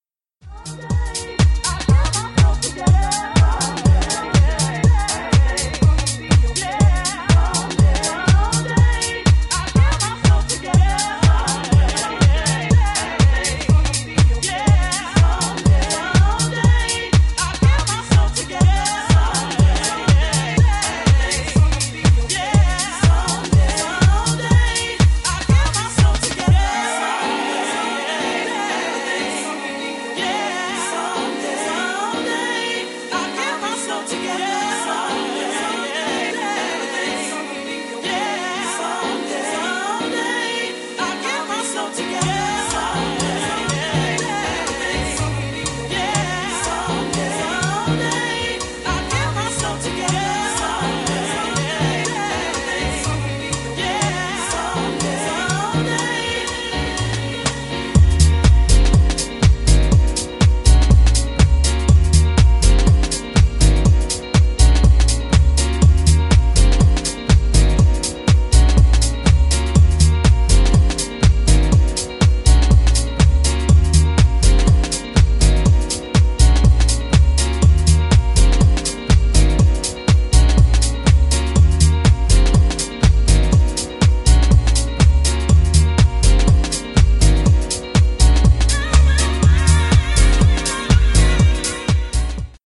ソウルフル＆ディープなハウスで超超超おすすめの1枚！！
ジャンル(スタイル) HOUSE / SOULFUL HOUSE